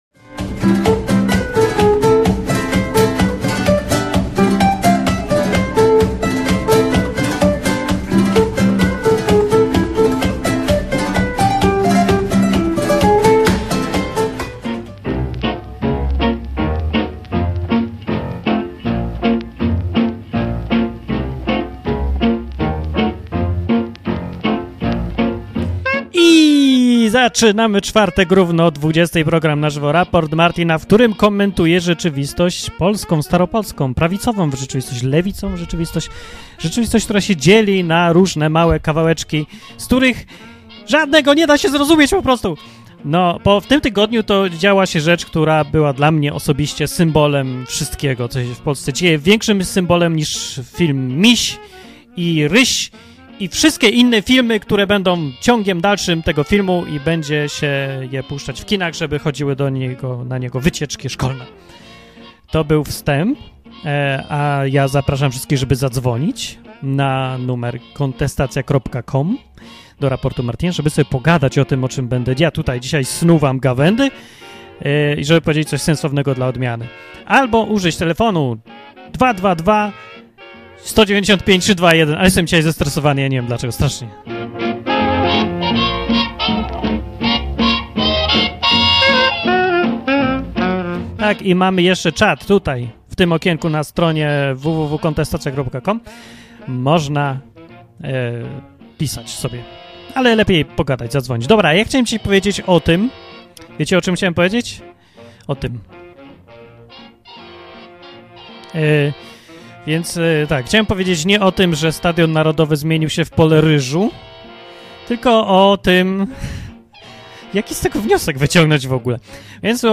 program satyryczno-informacyjny
Były informacje, komentarze, słuchacze, wszystko co najlepsze.